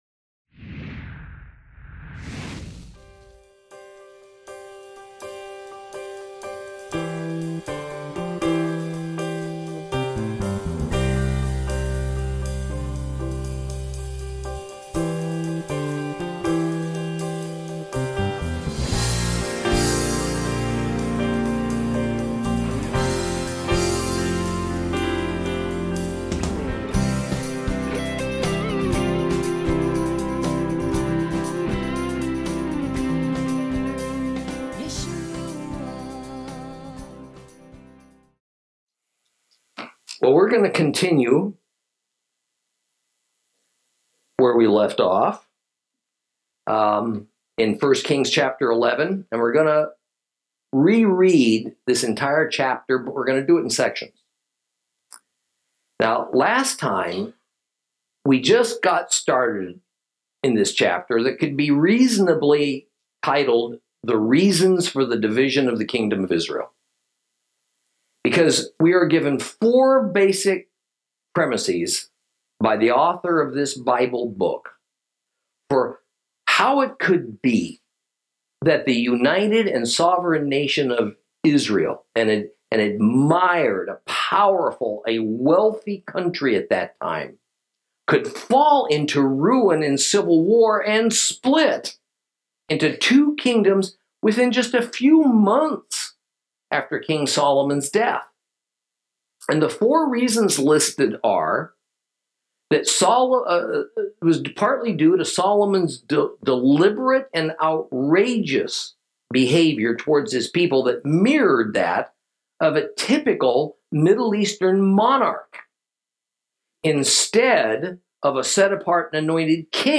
Lesson 19 Ch11 - Torah Class